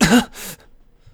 hurt5.wav